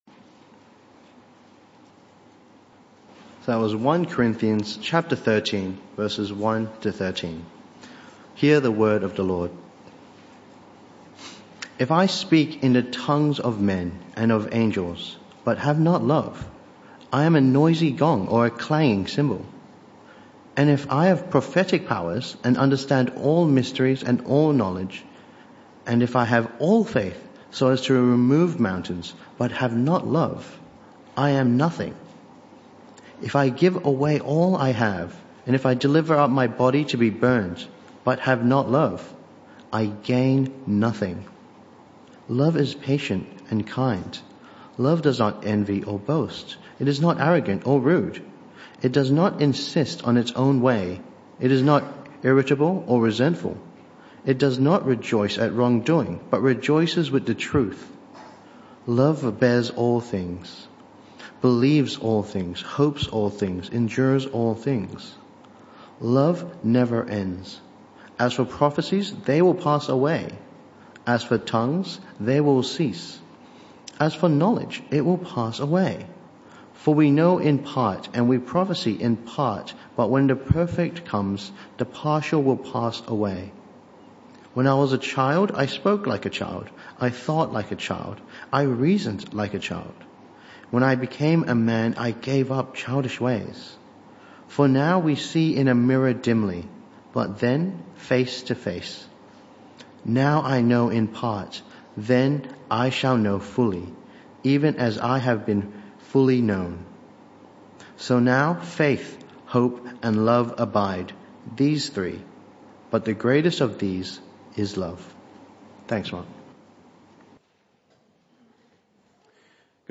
This talk was part of the AM+PM service series entitled A More Excellent Way (Talk 2 of 2).